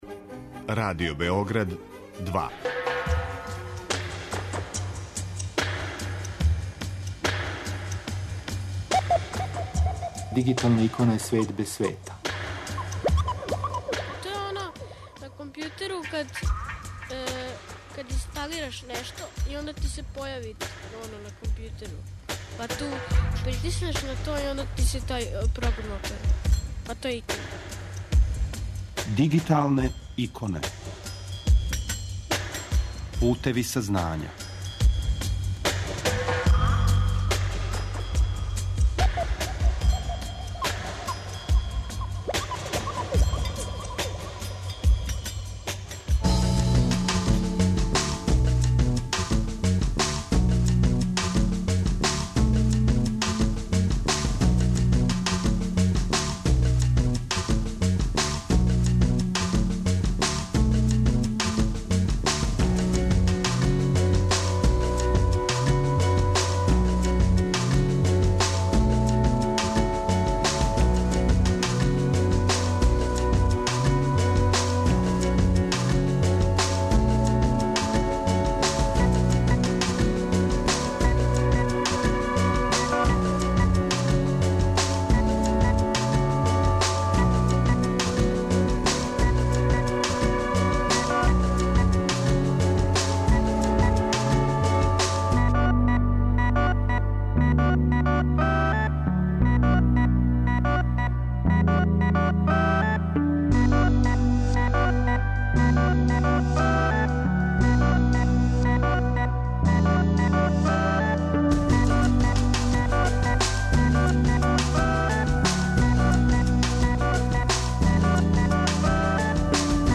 Уживо са нама - добитници овогодишњег признања "Дискоболос", за примену ИКТ-а у пословању, које је уручено у понедељак после подне у Скупштини града Београда, од стране Јединственог информатичког савеза Србије.